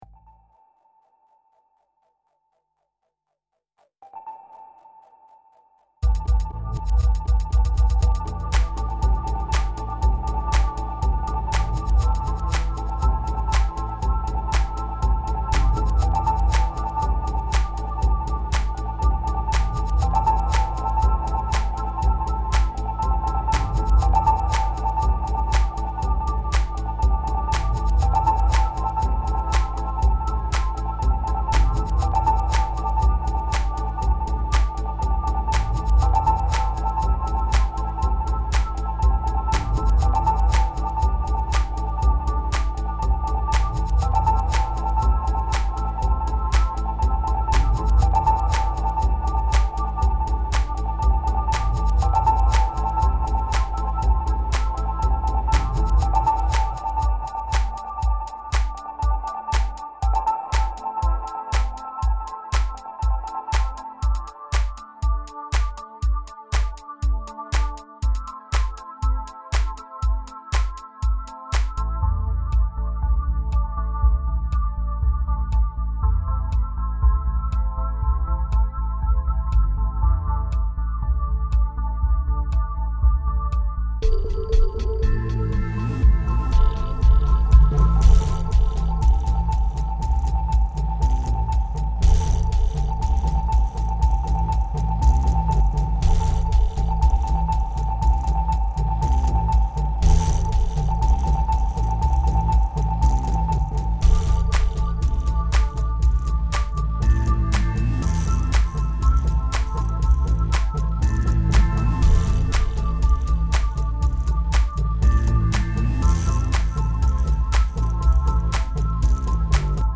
4. Genre: House